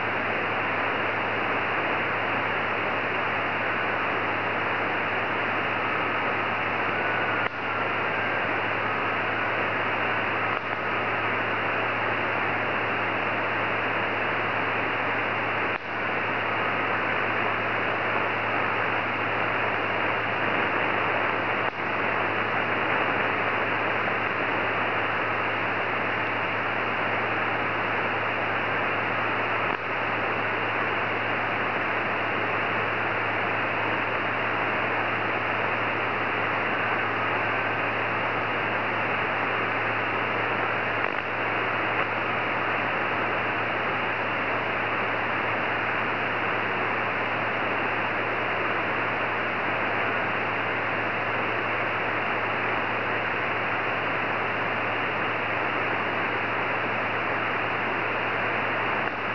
klikněte na následující nahrávky signálu JT65 (signál je podobný jako když jezdí hasiči :-)